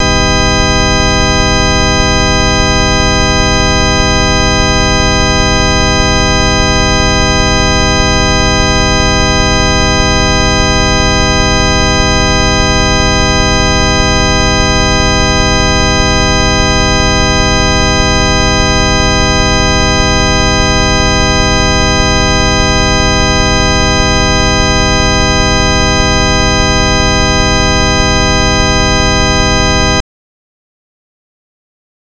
Audio Burn-In Test Track
The construction of the test track is a set of equal volume sine-waves with an underlying white noise floor approximately 6dBu below the amplitude of the tones.